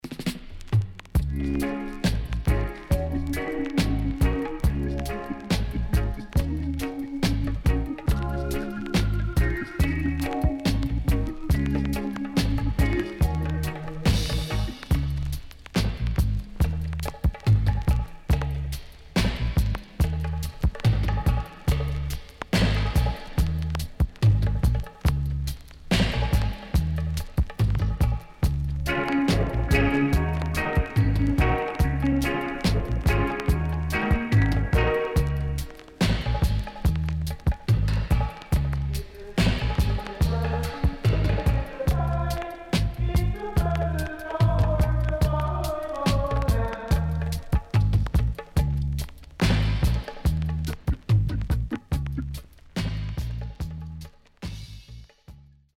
CONDITION SIDE A:VG〜VG(OK)
Good Roots Vocal
SIDE A:全体的にチリノイズがあり、少しプチノイズ入ります。